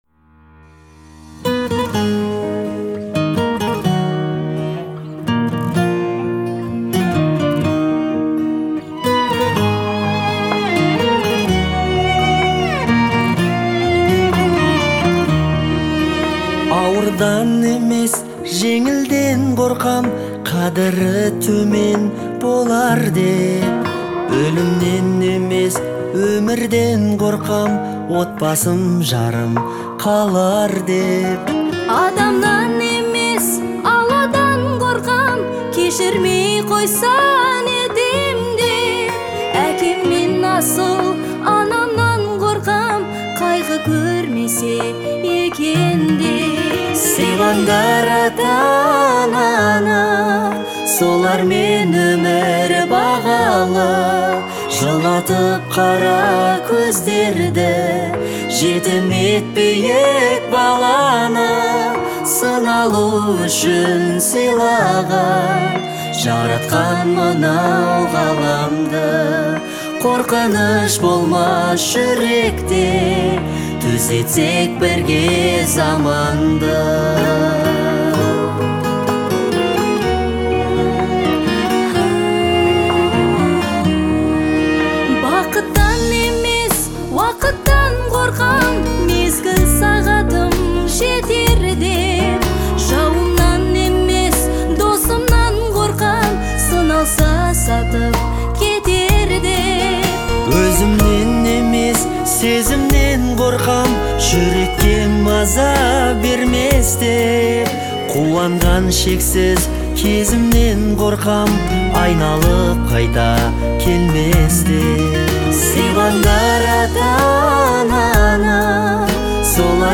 это эмоциональная композиция в жанре поп